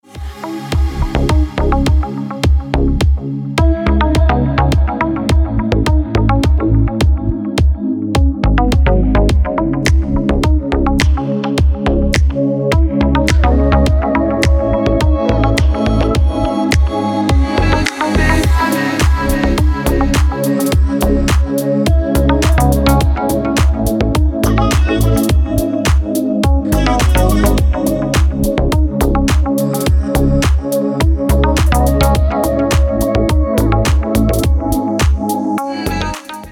• Качество: Хорошее
• Песня: Рингтон, нарезка
Спокойный трек на звонок телефона